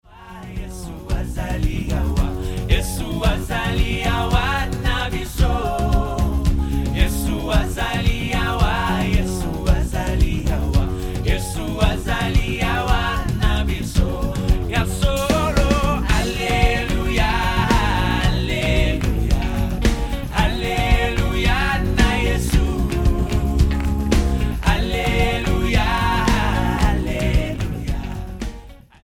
STYLE: Pop
sung with great passion in the local dialect.